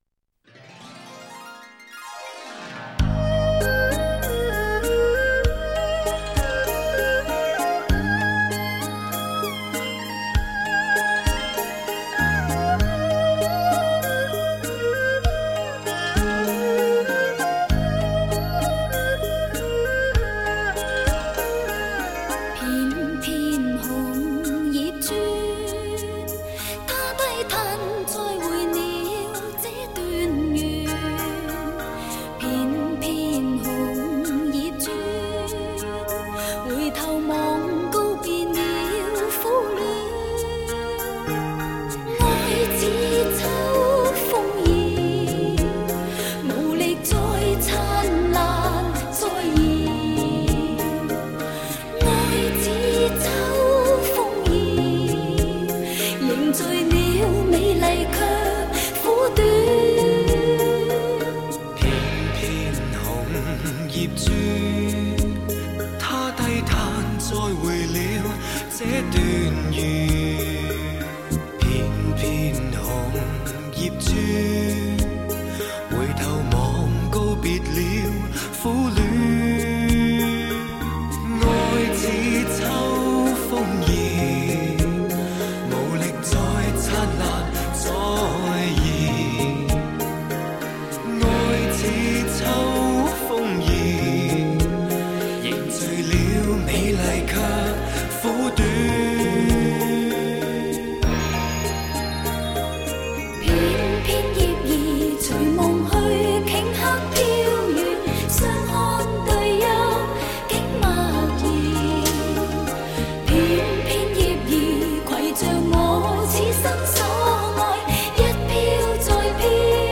整体的风格偏重抒情浪漫，歌曲多为易于上口的舒缓小曲，缺点自然是有的，但更多的是惊喜和叹服。
前者热情奔放、朝气蓬勃，后者温婉缠绵、流畅自然，两者都是不可多得的经典之作